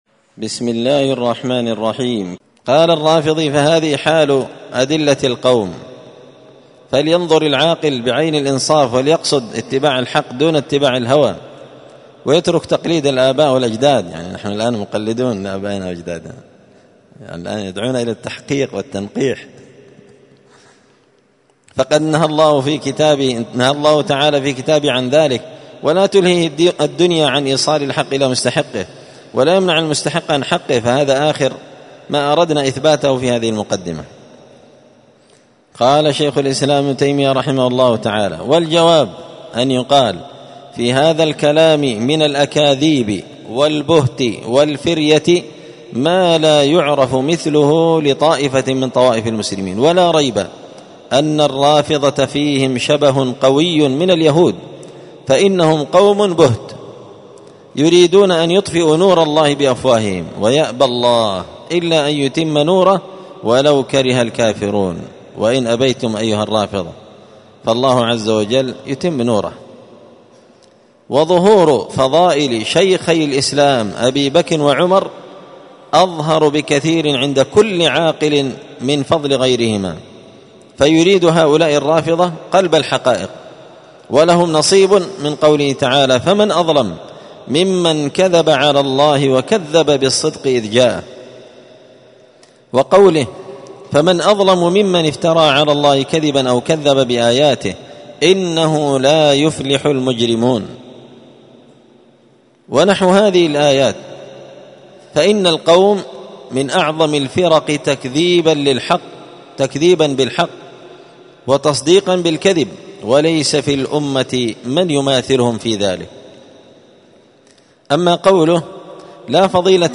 الأربعاء 19 ربيع الأول 1445 هــــ | الدروس، دروس الردود، مختصر منهاج السنة النبوية لشيخ الإسلام ابن تيمية | شارك بتعليقك | 75 المشاهدات
مسجد الفرقان قشن_المهرة_اليمن